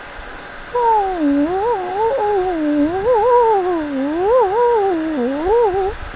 Tag: 吉隆坡 纹理 吉隆坡 车站